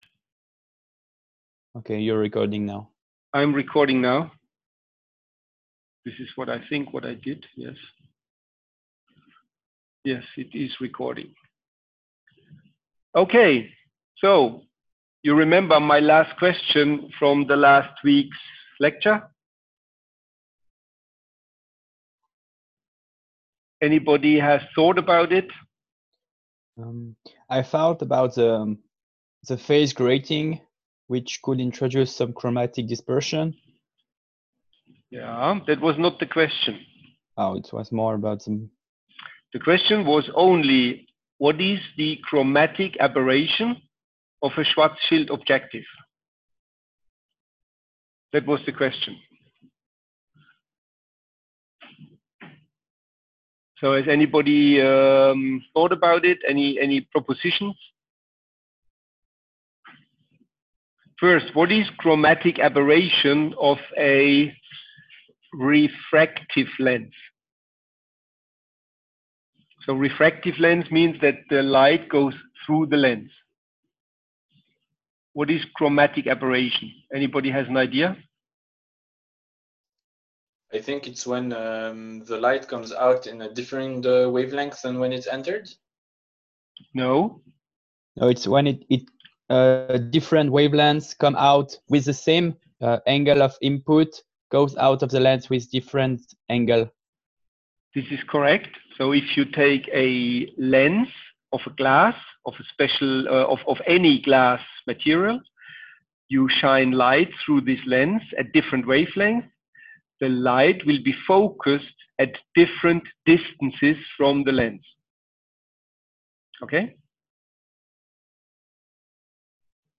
audio only lecture 3April2020.m4a